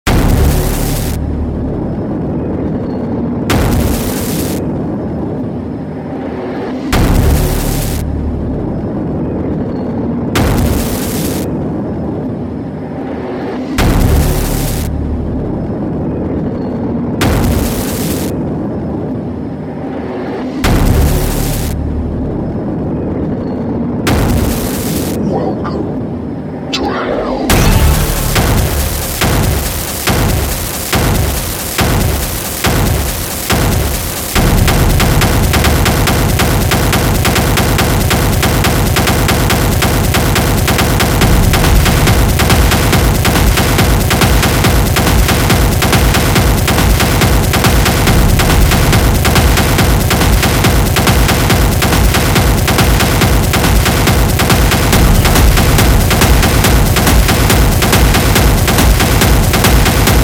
Электронная
балансируя между Harsh EBM и Power Noise.
брутальные ритмы и хаотичные биты